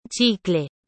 • Le « CH » se prononce [tche] comme dans le mot français tchèque ou le terme espagnol « chicle » (chewing-gum).